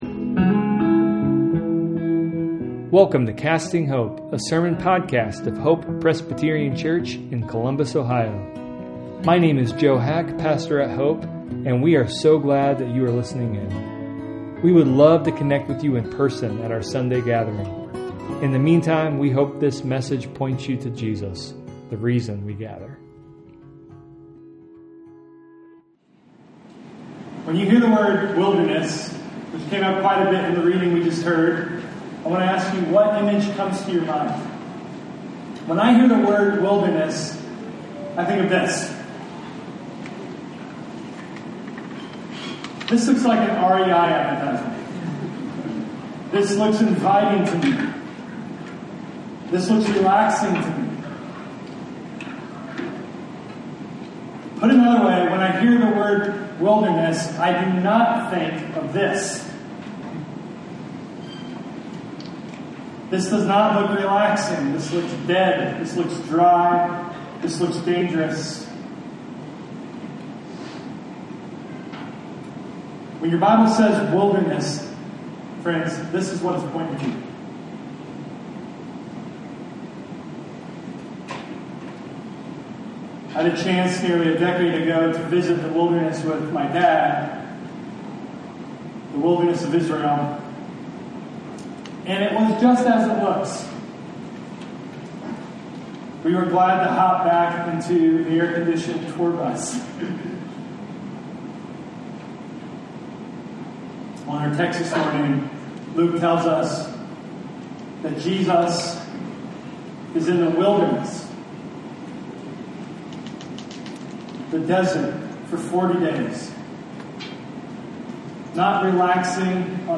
A sermon podcast of Hope Presbyterian Church in Columbus, Ohio.